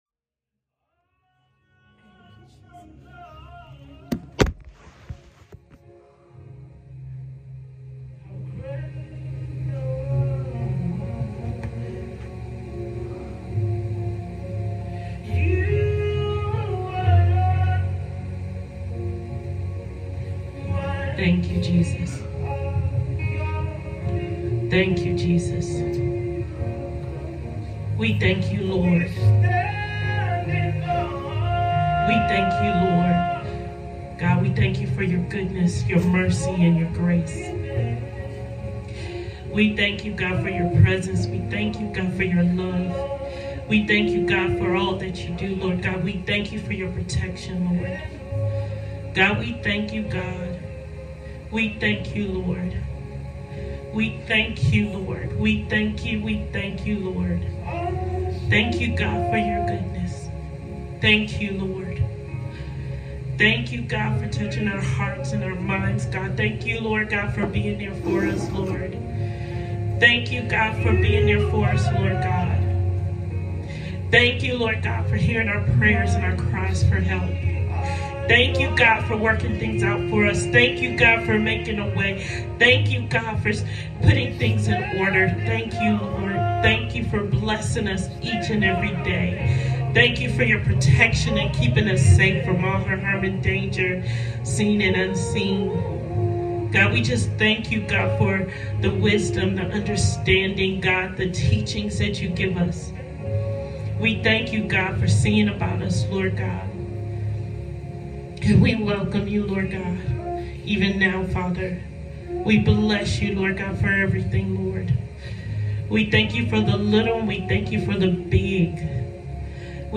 Prayer Tuesday Night
Prayer-Tuesday-Night.mp3